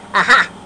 Ah Ha (cartoon) Sound Effect
Download a high-quality ah ha (cartoon) sound effect.
ah-ha-cartoon.mp3